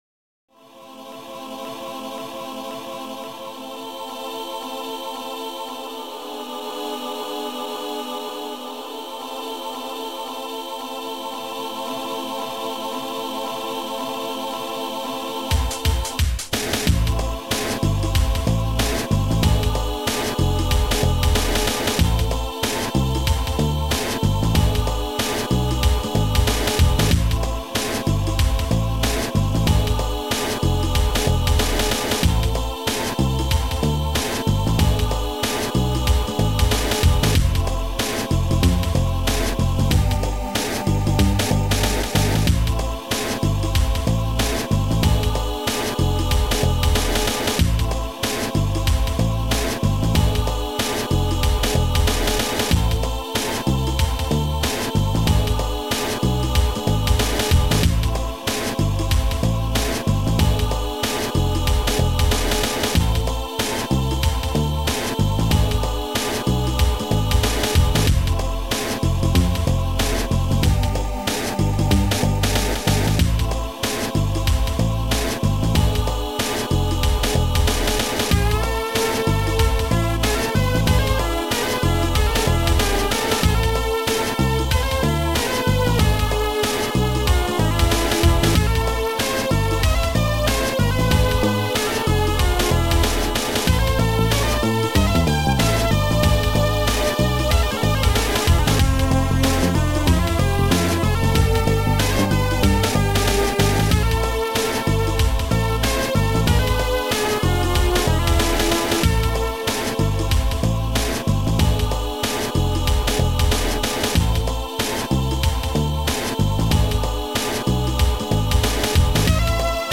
Sound Format: Noisetracker/Protracker
Sound Style: Ambient